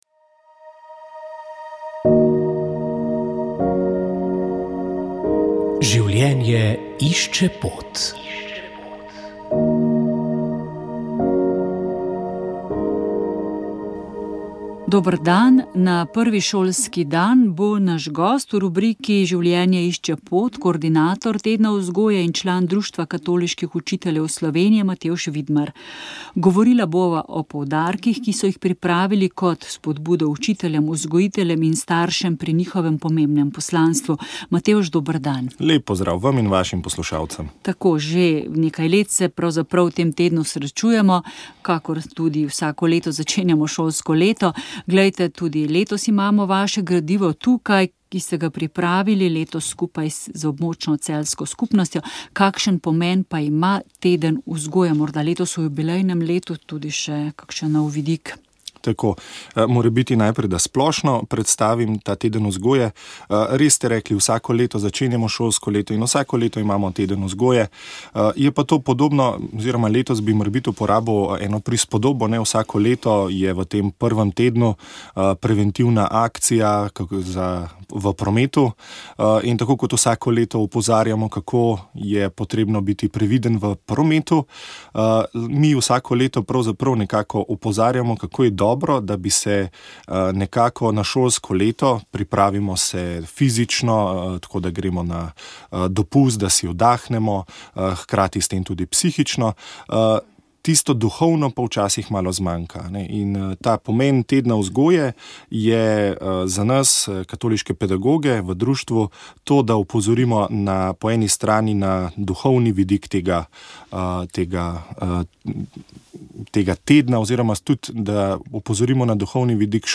Uvodoma smo prisluhnili duhovnemu nagovoru, ki ga je pripravil škof Andrej Glavan.
Ker pa smo pred praznikom Božjega usmiljenja, smo zmolili tudi rožni venec Božjega usmiljenja. Ob sklepu je sledila še molitev Pot luči.